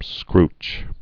(skrch)